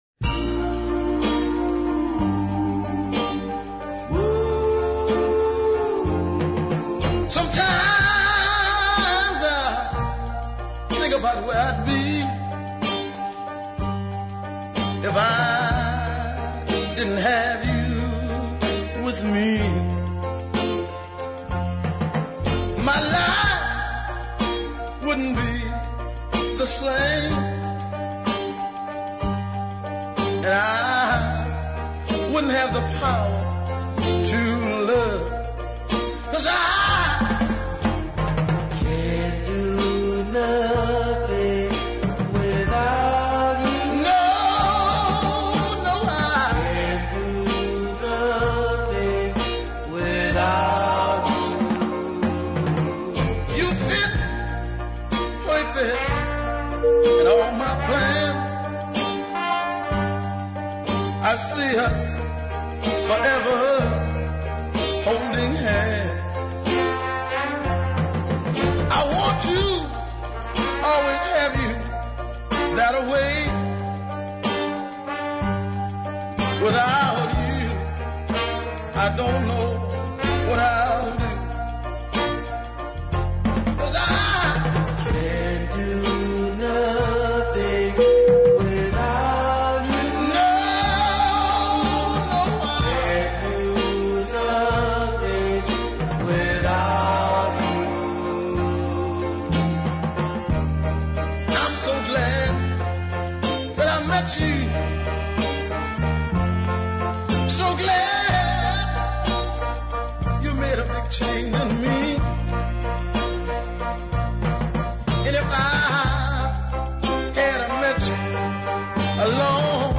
a great southern soul ballad